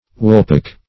woolpack - definition of woolpack - synonyms, pronunciation, spelling from Free Dictionary
Search Result for " woolpack" : The Collaborative International Dictionary of English v.0.48: Woolpack \Wool"pack`\, n. A pack or bag of wool weighing two hundred and forty pounds.